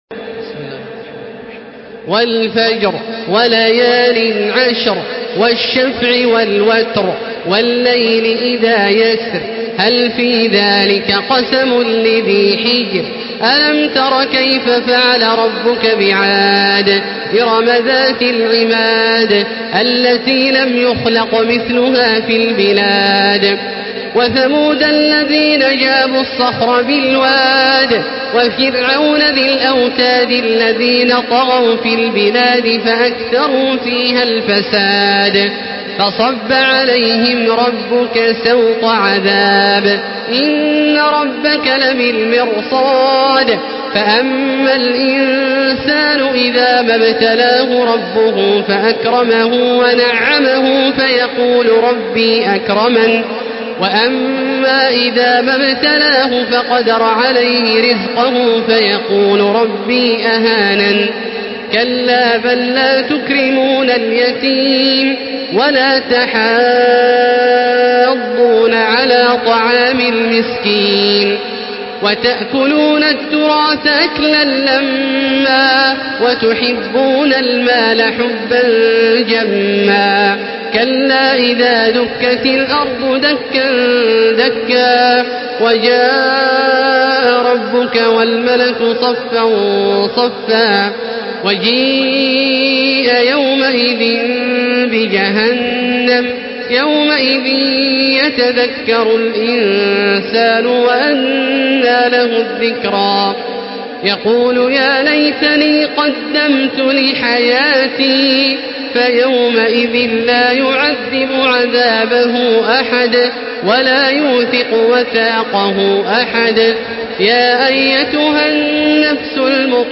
Surah Fecr MP3 by Makkah Taraweeh 1435 in Hafs An Asim narration.
Murattal Hafs An Asim